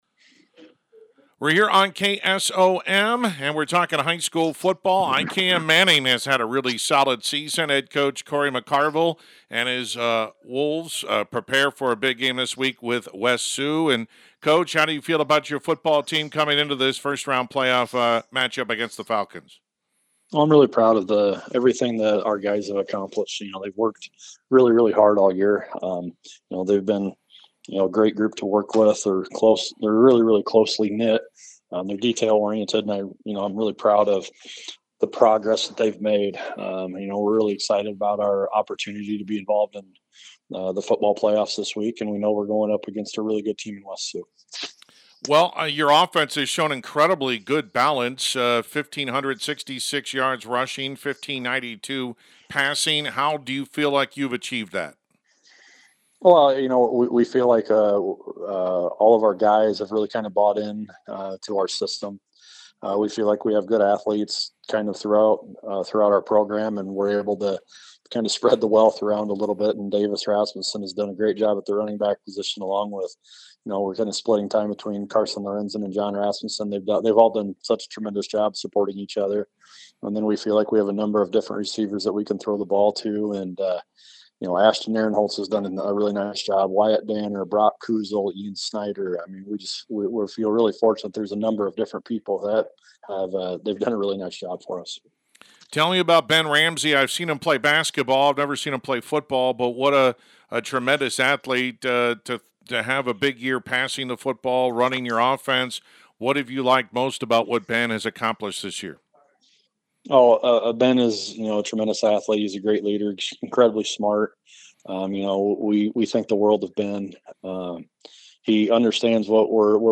Complete Interview
ikm-manning-football-10-21.mp3